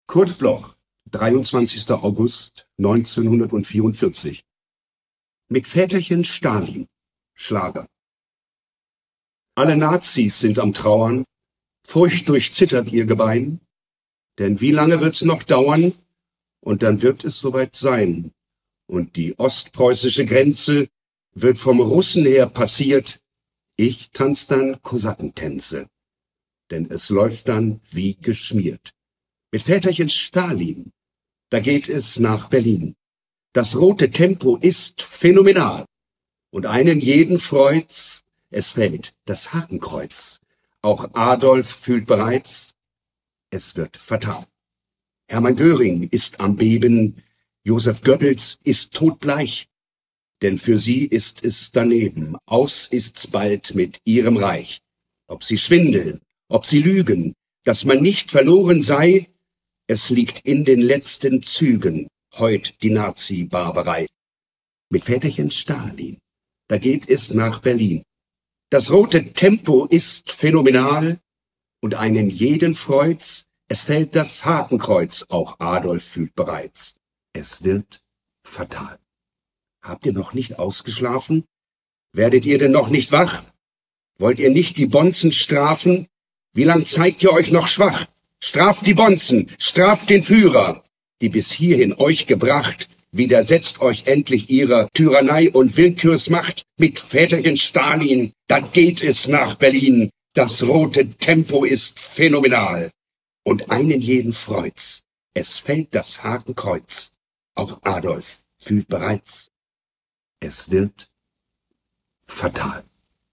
Mit Väterchen Stalin (Schlager)